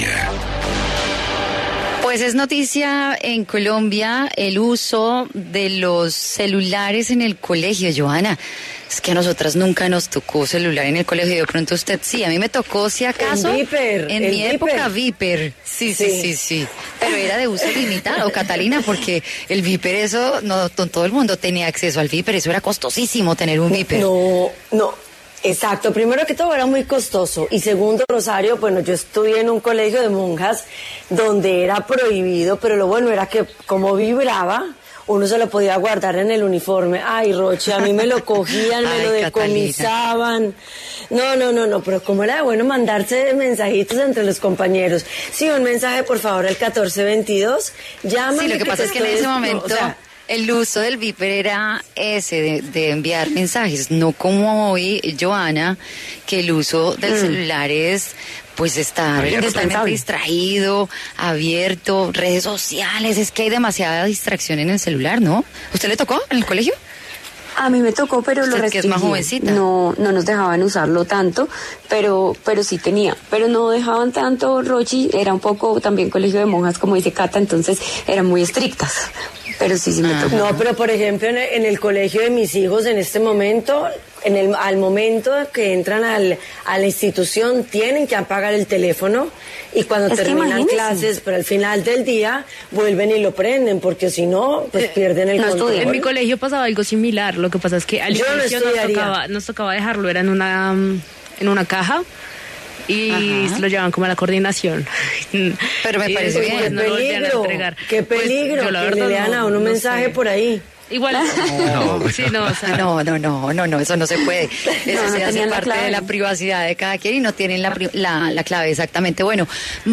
El representante Rodrigo Rojas explicó en W Fin De Semana el proyecto de ley que regula el uso de los teléfonos móviles en los colegios.